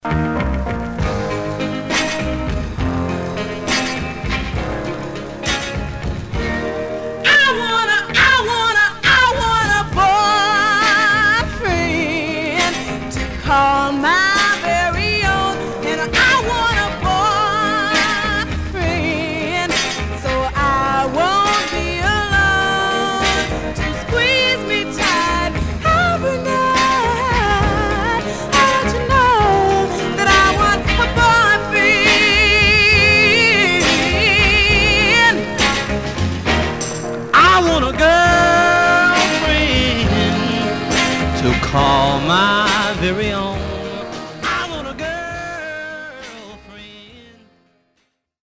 Some surface noise/wear Stereo/mono Mono